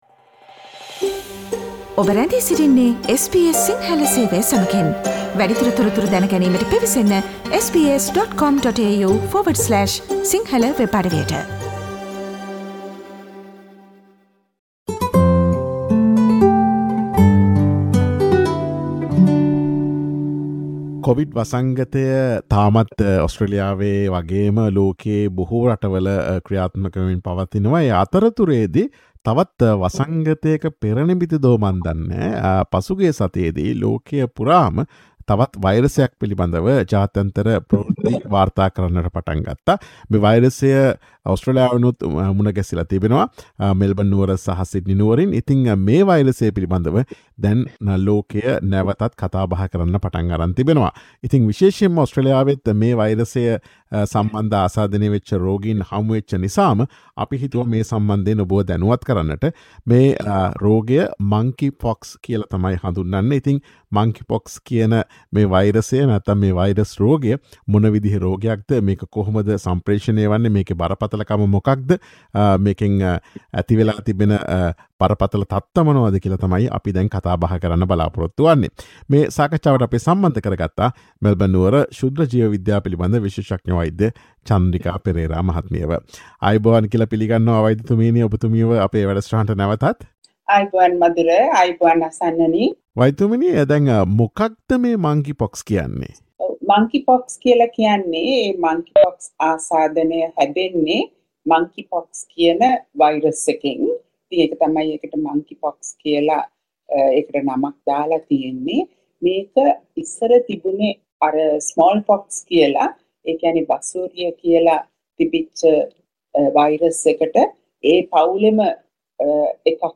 මේ දිනවල ඔස්ට්‍රේලියාව ඇතුළු ලෝකයේ පැතිරී යාමේ අවදානමක් ඇති නවතම වයිරස් රෝගය වන Monkeypox රෝගය පිළිබඳ SBS සිංහල ගුවන් විදුලිය සිදුකළ සාකච්ඡාවට සවන් දීමට ඉහත ඡායාරූපය මත ඇති speaker සලකුණ මත click කරන්න.